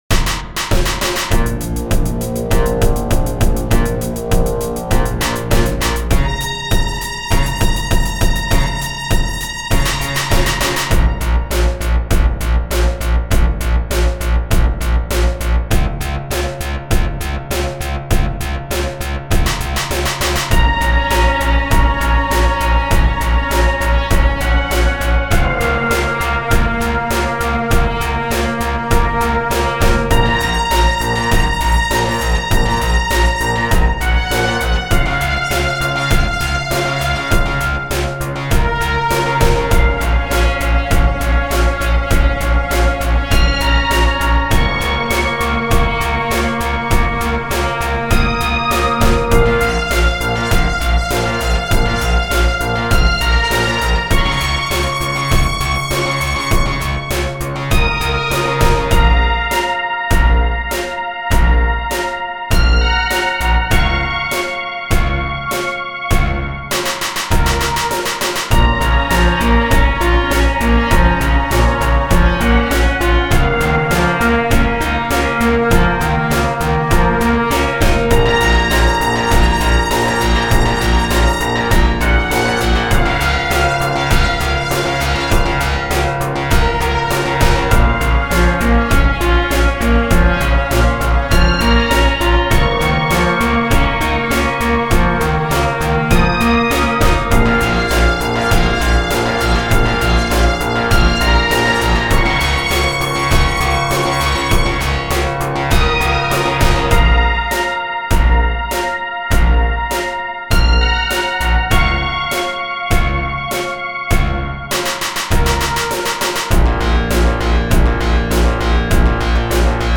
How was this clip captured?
These tracks have been sequenced on Linux with Rosegarden.